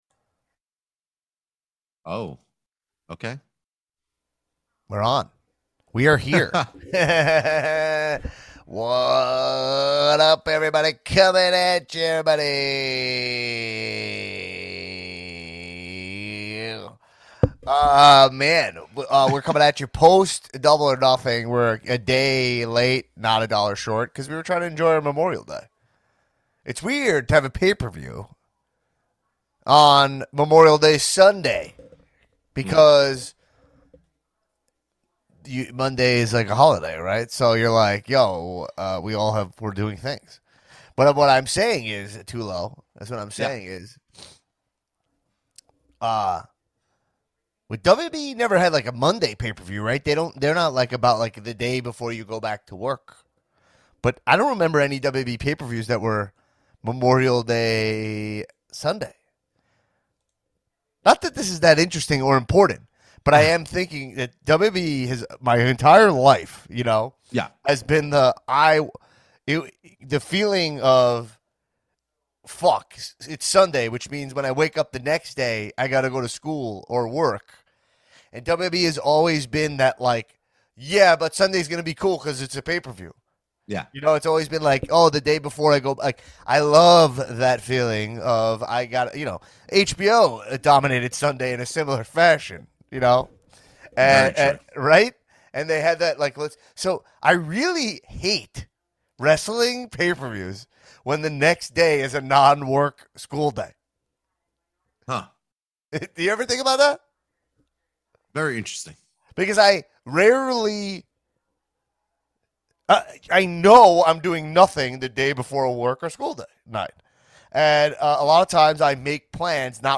Plus we go on an ACCIDENTAL Batman detour! We listen to Seth Rollins RAW promo live on the air.